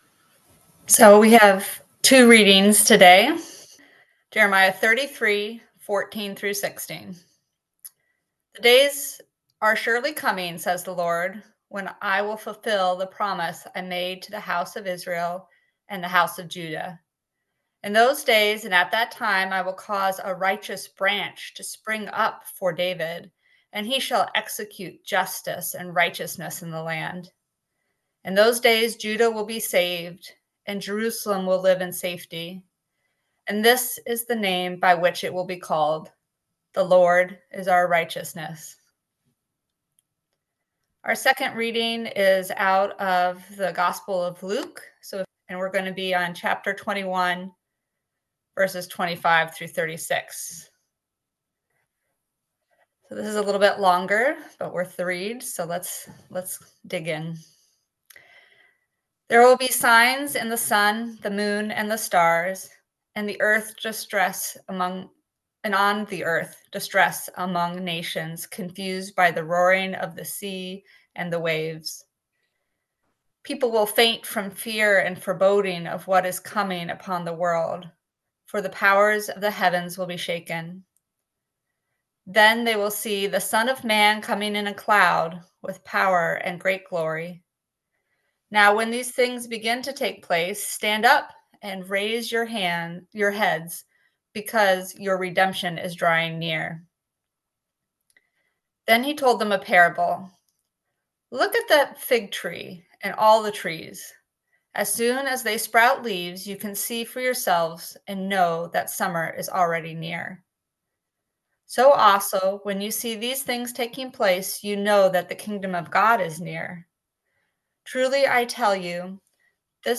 Listen to the most recent message from Sunday worship at Berkeley Friends Church, “Drawing Near.”